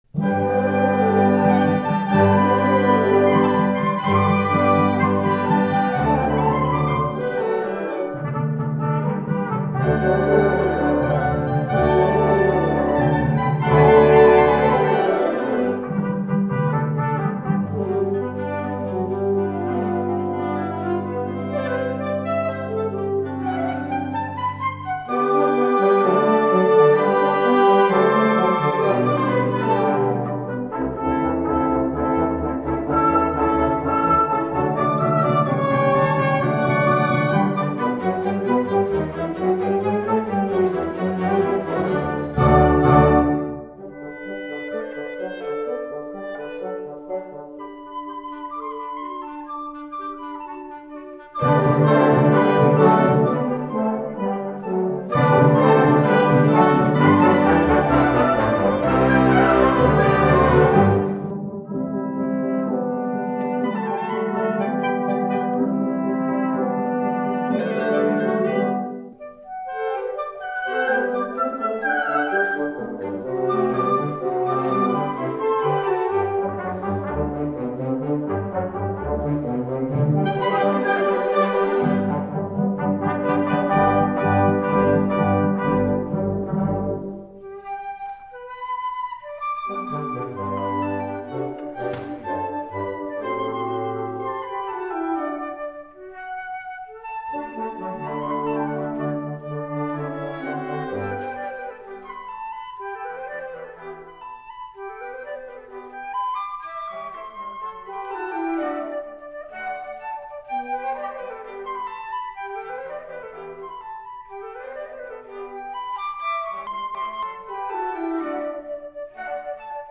Voicing: Flute Duet w/ Band